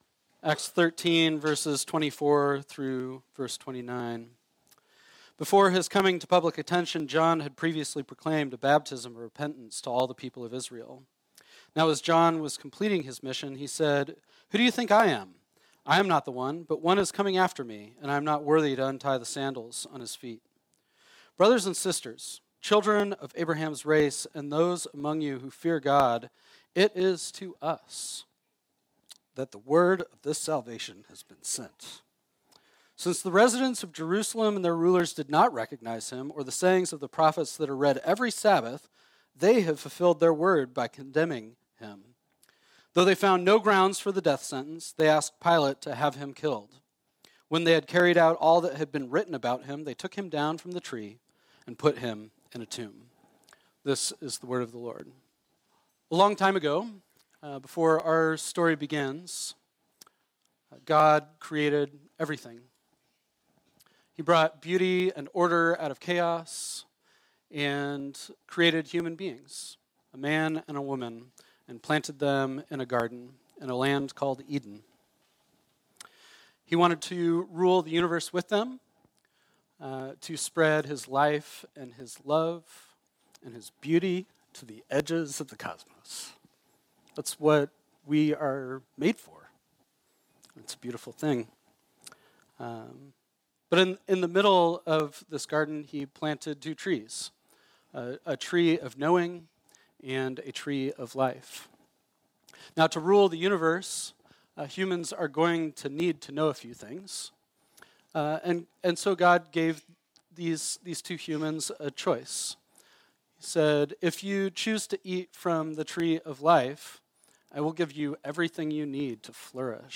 This sermon was originally preached on Friday, April 7, 2023.